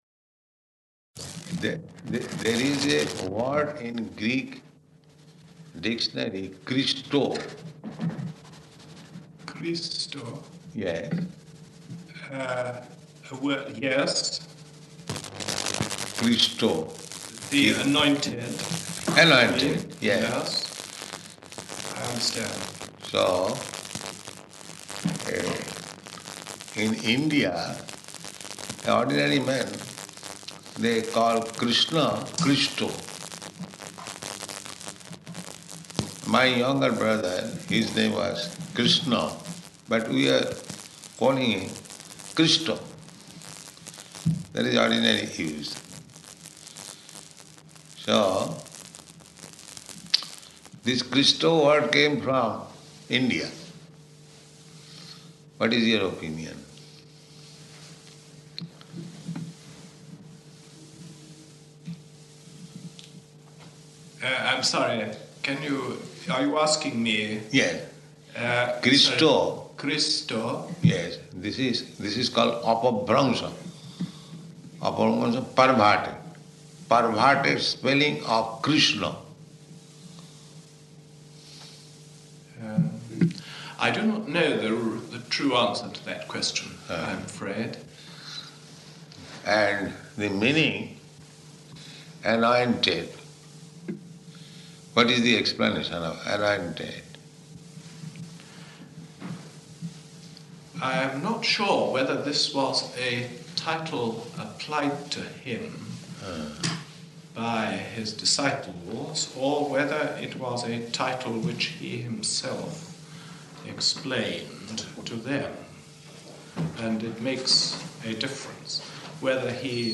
-- Type: Conversation Dated: July 10th 1973 Location: London Audio file